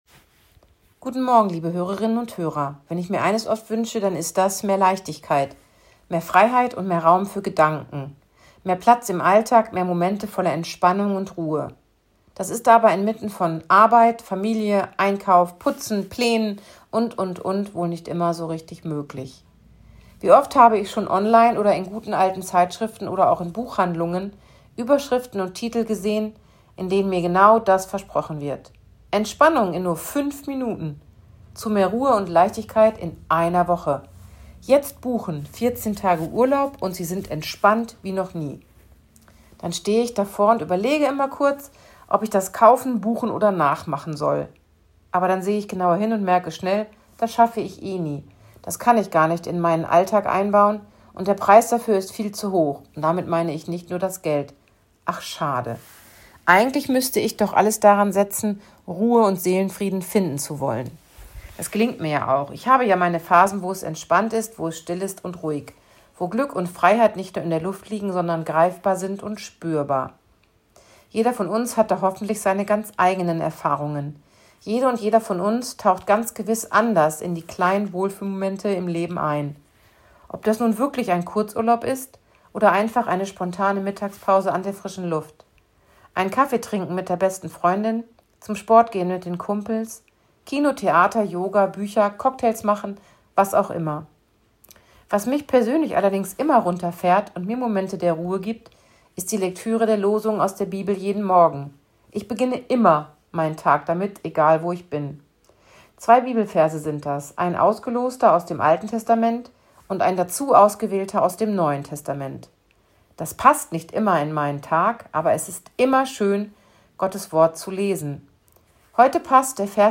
Radioandacht vom 28. August
radioandacht-vom-28-august-3.mp3